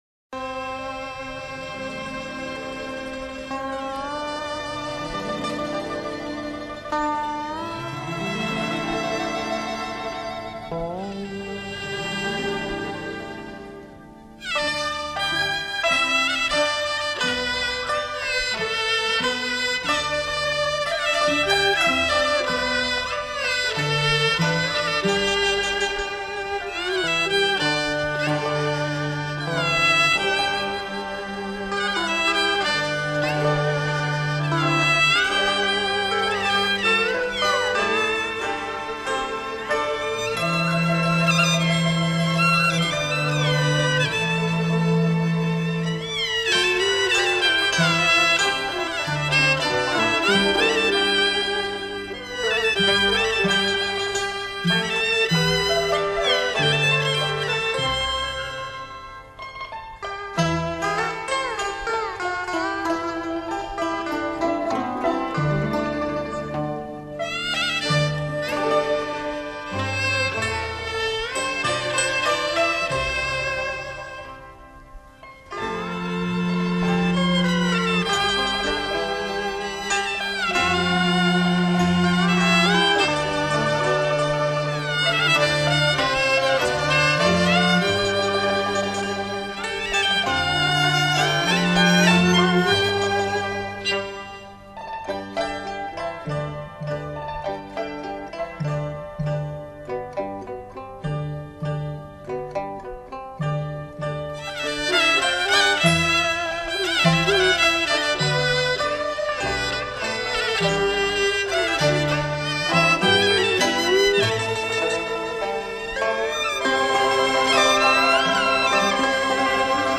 本碟收录了我国优秀的民族乐曲，淡而清雅，
为最受欢迎的中国品茶音乐，不妨试试！